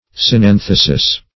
Synanthesis synonyms, pronunciation, spelling and more from Free Dictionary.
synanthesis.mp3